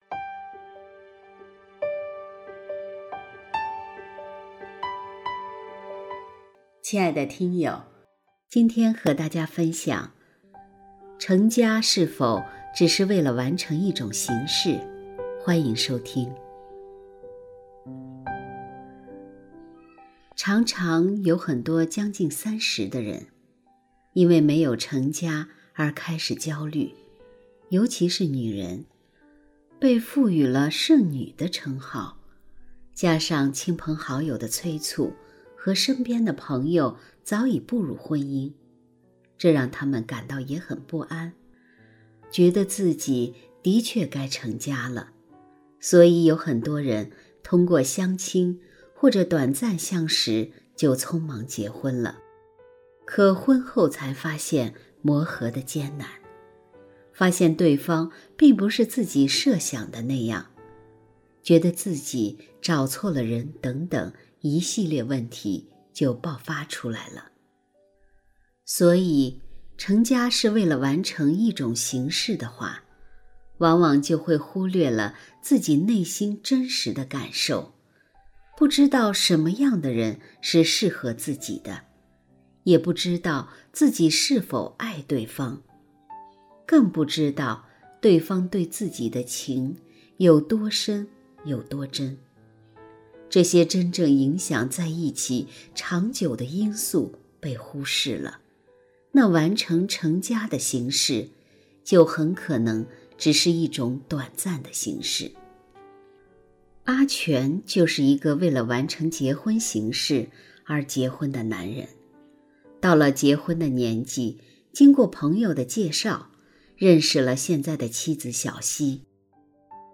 成家是否只是一种短暂的形式（混缩）-剪辑.mp3